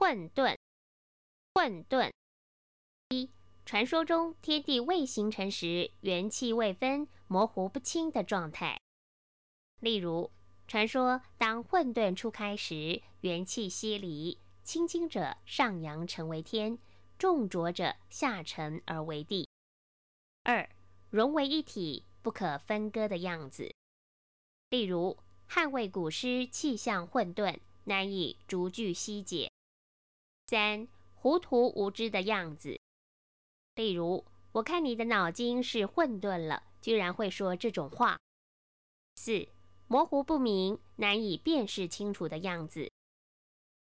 Advanced Information 【沌】 水 -4-7 Word 混沌 Pronunciation ㄏㄨㄣ ˋ ㄉㄨㄣ ˋ ▶ Definition 傳說中天地未形成時元氣未分、模糊不清的狀態。